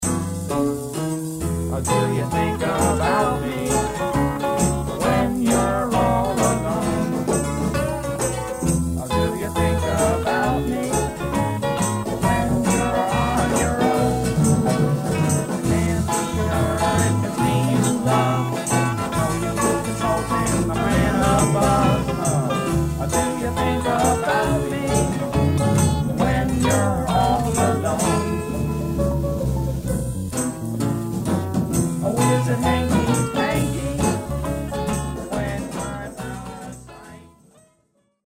early PS demo